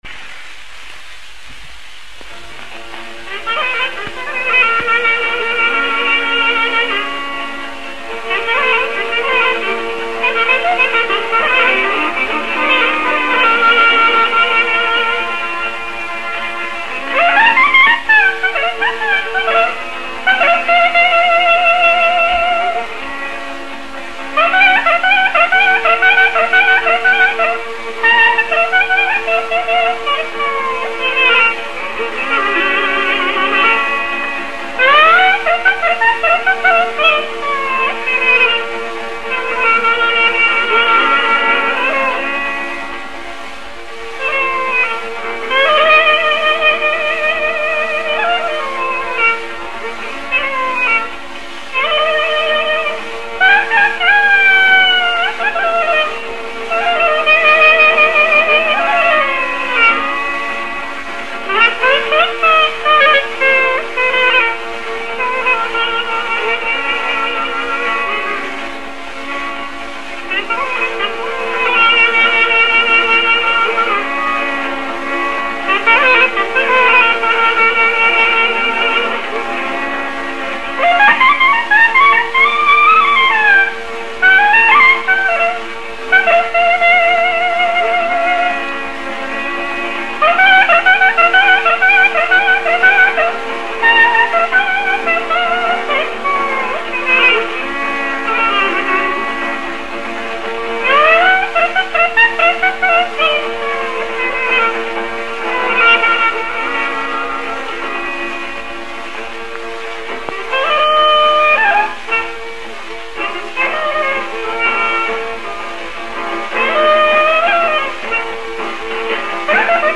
“Kabaja e Mediut” shfaqet si kaba me dy pjesë: kaba vajtuese dhe valle e gëzuar.
Tipike për kabanë e tij është motivi vajtimor mbi të cilin zhvillohet pothuajse e gjithë pjesa e parë e kabasë, dialogu marrje–prerje mes gërnetës dhe violinës, nën ison konstante të llautës në lartësinë e notës Si b. Kabaja ka një dhimbje të përmbajtur e cila herë pas here shpërthen në kulminacione patetike të cilat lidhen me gjendjen e interpretuesit.
Sazet e tij kanë qenë të përbëra nga gërnetë-fyelli, qemania, buzuku dhe llauta.